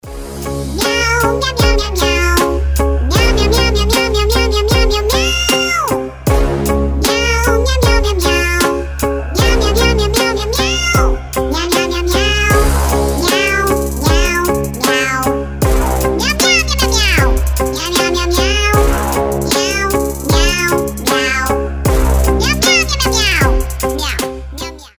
позитивные
веселые
Cover
смешные
мяу
Прикольный мяукающий кавер